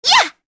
Shamilla-Vox_Attack3_kr.wav